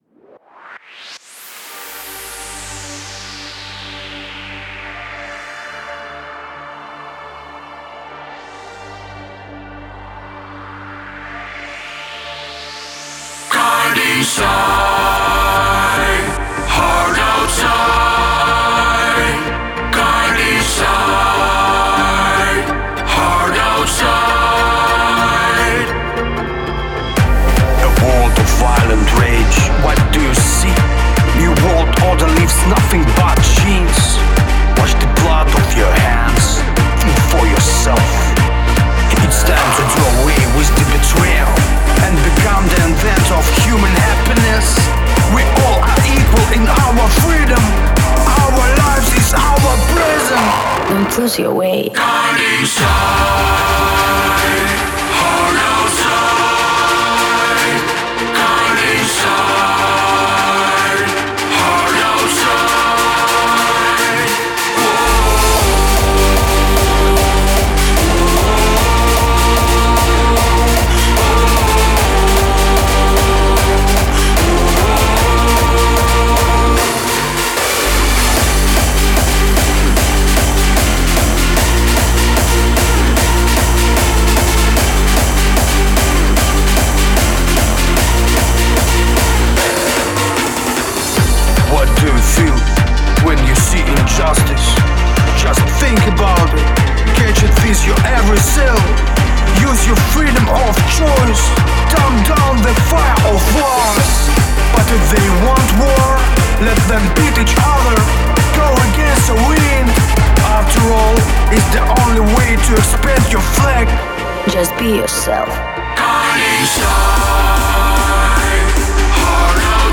яркая и энергичная песня в жанре трэп и электронная музыка